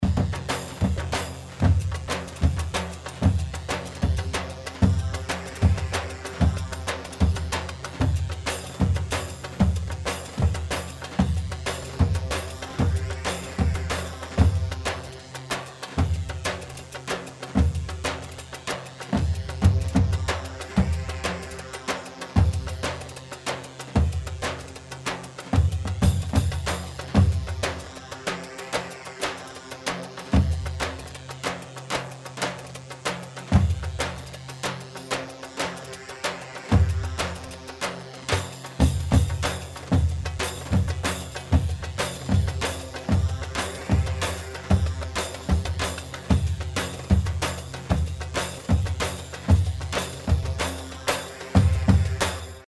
Iraqi